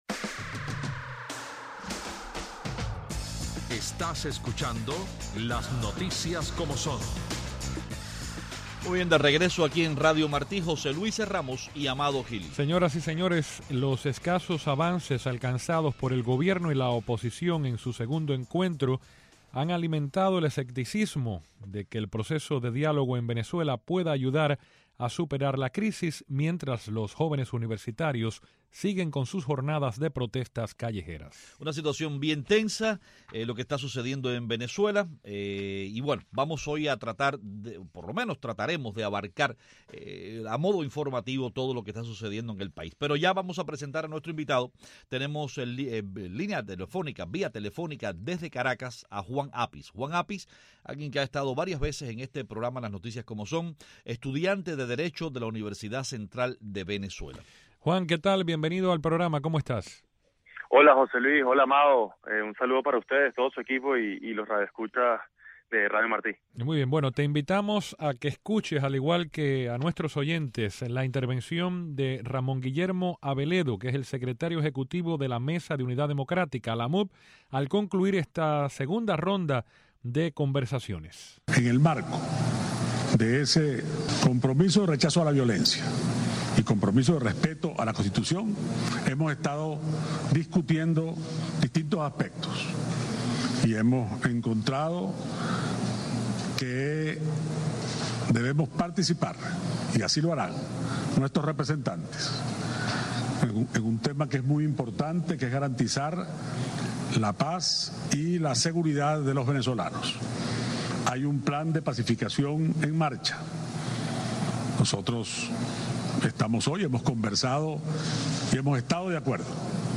También ofrecemos fragmentos de sus declaraciones.